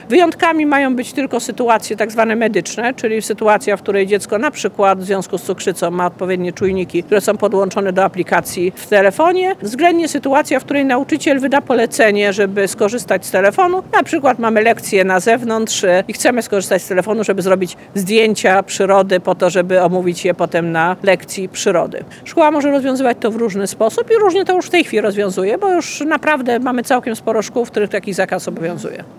Katarzyna Lubnauer bierze dziś (23.03) udział w spotkaniu z dyrektorami szkół i nauczycielami w ramach ogólnopolskiej trasy informacyjnej „Kierunek: Kompas Jutra”. Wydarzenie odbywa się w Szkole Podstawowej nr 1 w Biłgoraju.